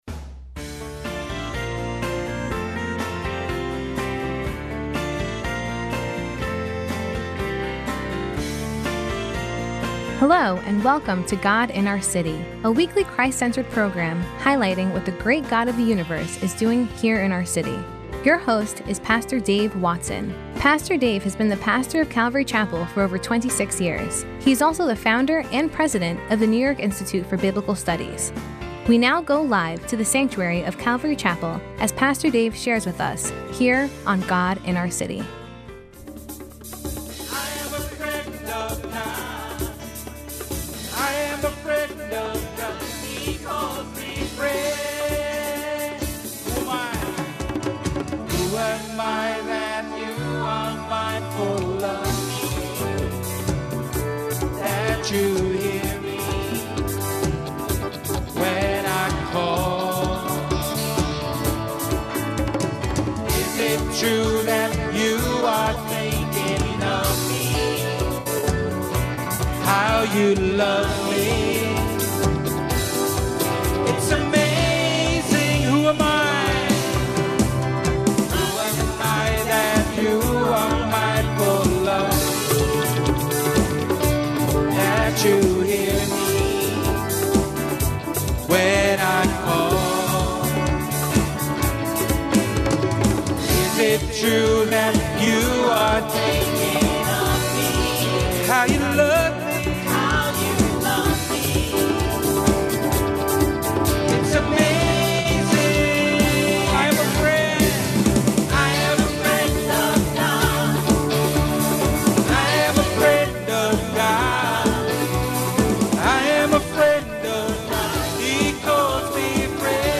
Podcast of “God in Our City” from yesterday’s service at Calvary Chapel, Friend Day 11/6/2016